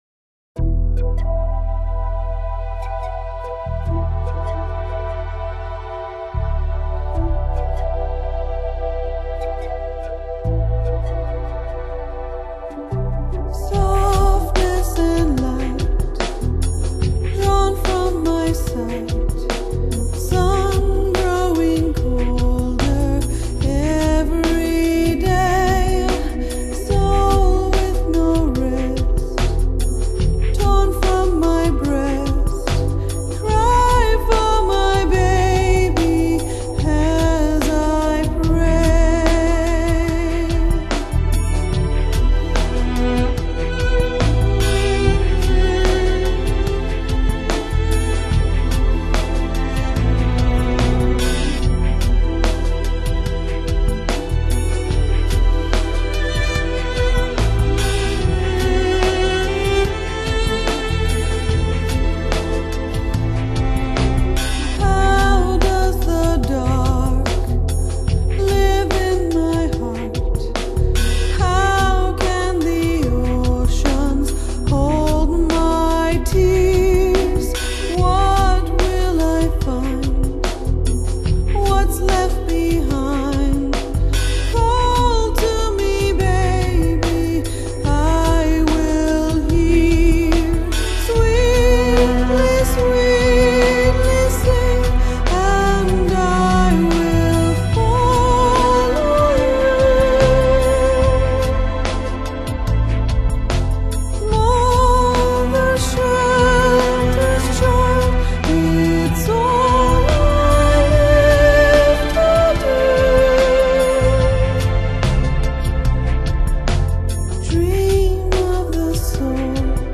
音乐类别：天籁和声
专辑中有独奏表演。